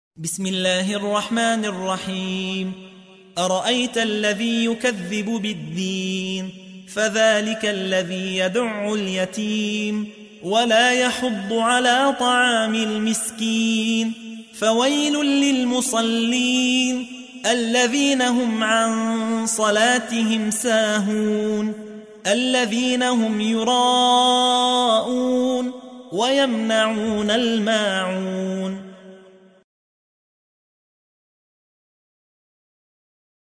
تحميل : 107. سورة الماعون / القارئ يحيى حوا / القرآن الكريم / موقع يا حسين